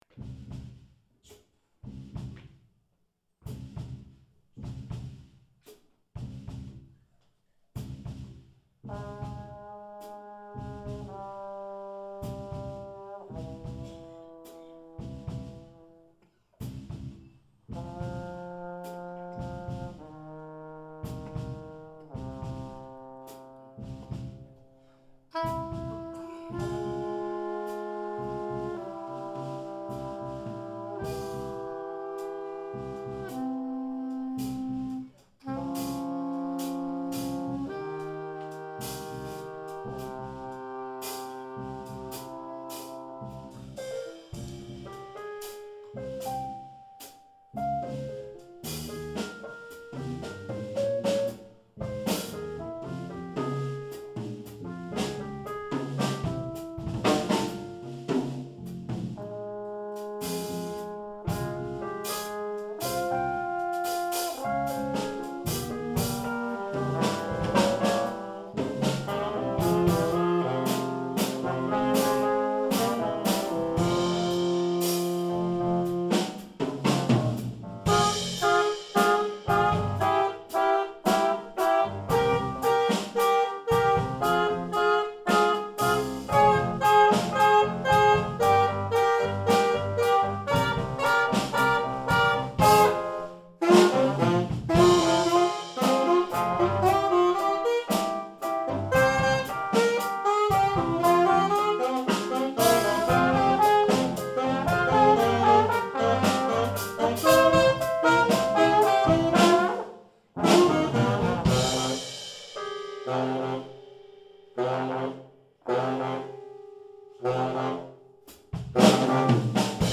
sax, tb, g, b, dr
· Genre (Stil): Jazz